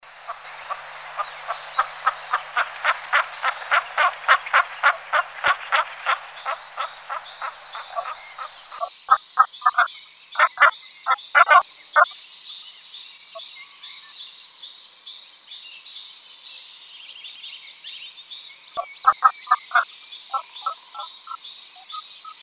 Звуки глухаря
Голос самки птицы записанный в Чехии